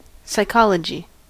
Ääntäminen
Ääntäminen US US : IPA : /saɪˈkɑlədʒi/